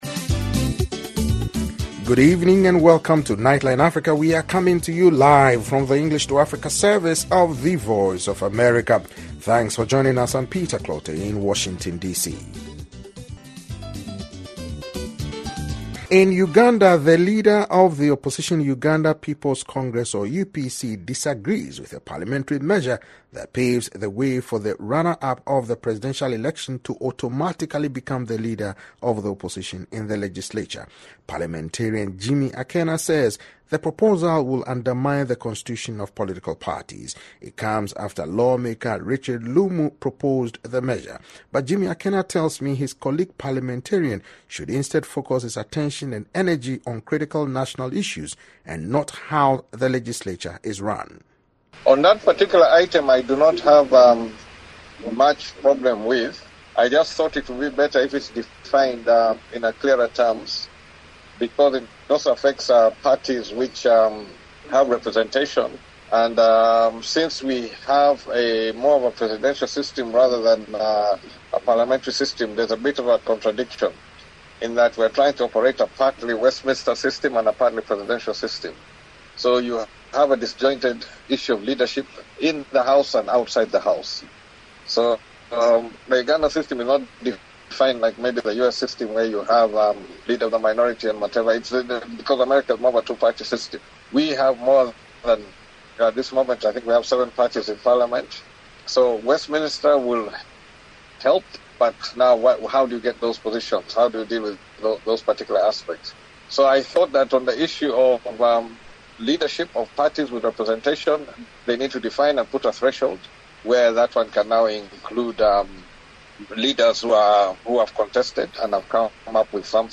The Voice of America (VOA)’s Nightline Africa interview with the UPC Pres Hon. Jimmy Akena on 7th September about Hon.Lumu’s proposed Bill.